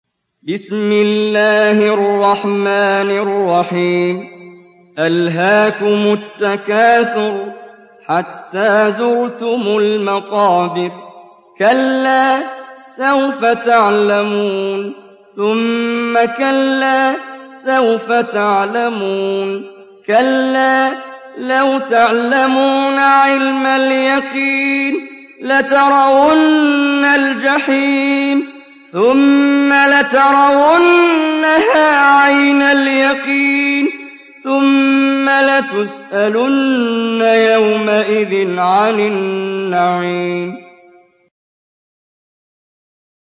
Surat At Takathur Download mp3 Muhammad Jibreel Riwayat Hafs dari Asim, Download Quran dan mendengarkan mp3 tautan langsung penuh